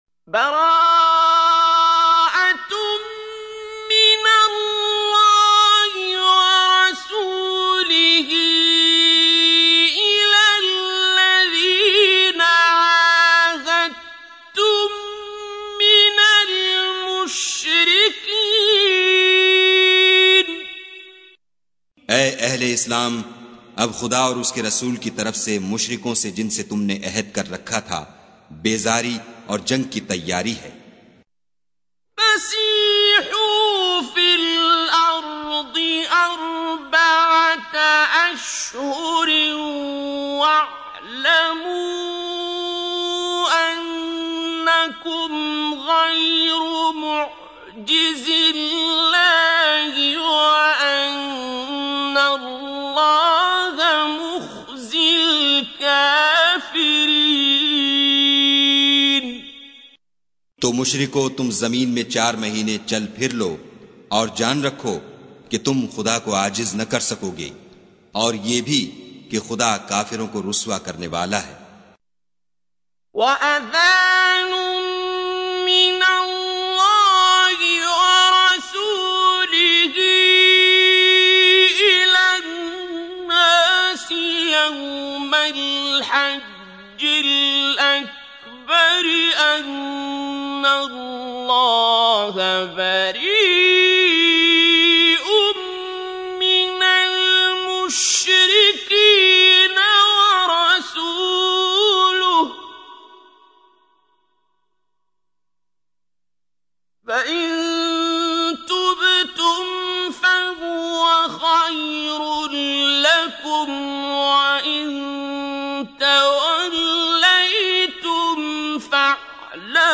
Listen Tilwat in the voice of Qari Abdul Basit As Samad